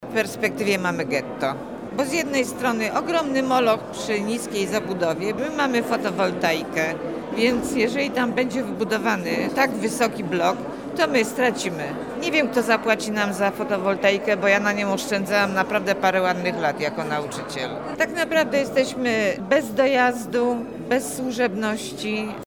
Wagnera-mieszkancy.mp3